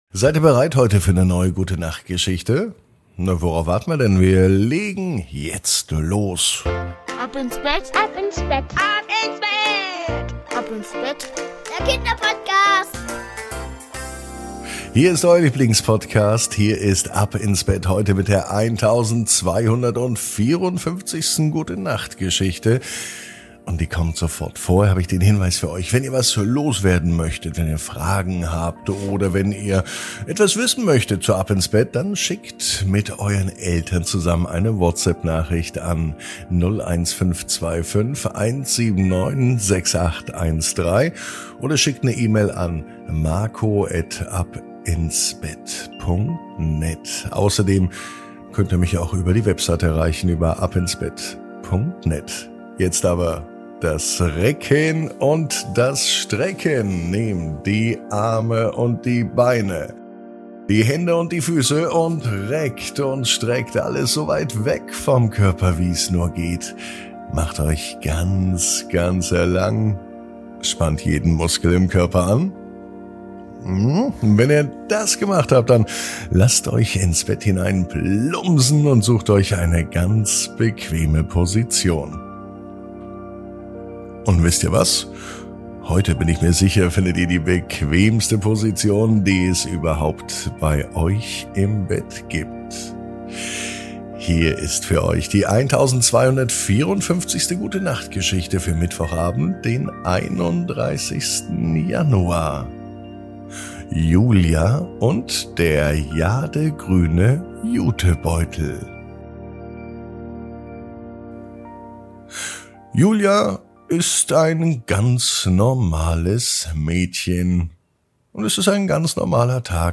Die Gute Nacht Geschichte für Mittwoch